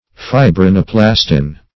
Search Result for " fibrinoplastin" : The Collaborative International Dictionary of English v.0.48: Fibrinoplastin \Fi`bri*no*plas"tin\, n. [Fibrin + Gr.